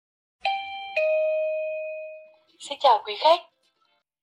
Âm thanh Xin chào quý khách (Giọng nữ, Mẫu số 4)
Description: Âm thanh “Xin chào quý khách” (Giọng nữ, Mẫu số 4) là lời chào tự động được phát ra với giọng nữ rõ ràng, ấm áp và thân thiện. Giọng nói được điều chỉnh nhịp nhàng, mang lại cảm giác chuyên nghiệp và lịch sự, phù hợp để sử dụng trong các hệ thống tổng đài, cửa hàng, nhà hàng hay trung tâm dịch vụ...
am-thanh-xin-chao-quy-khach-giong-nu-mau-so-4-www_tiengdong_com.mp3